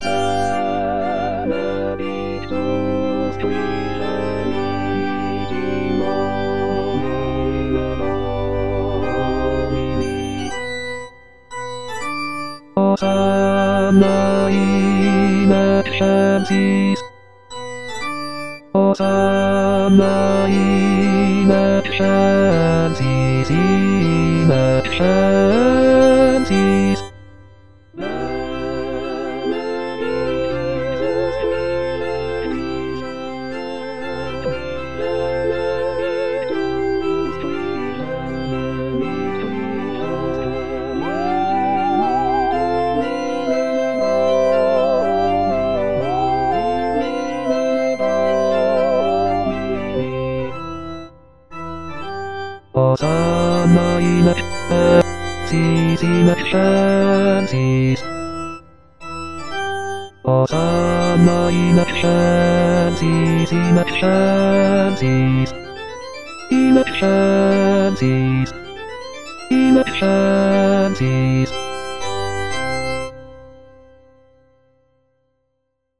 Les aides traditionnelles avec voix de synthèse
Benedictus-Basse
Benedictus-Basse.mp3